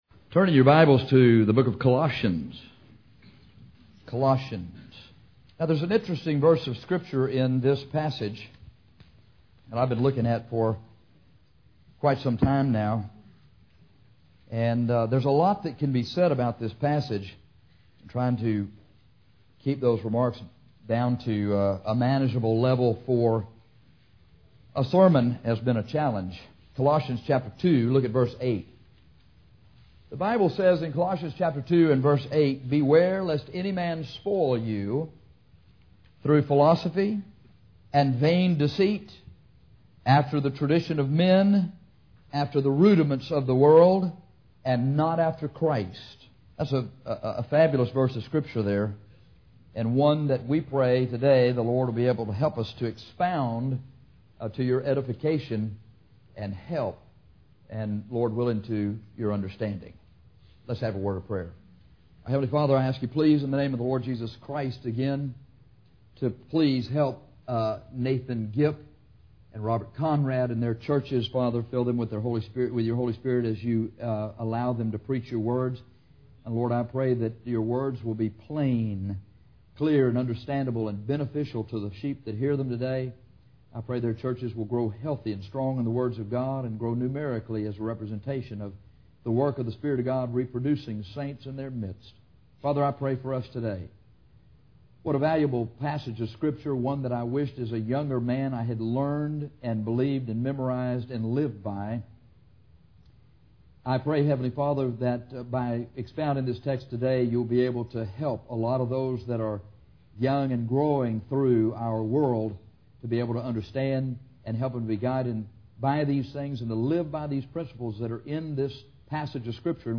Before we get into the sermon Beware lest any man spoil you, it would be well for us to make sure we understand the things of which we are to beware and what can happen to us as a result of these things.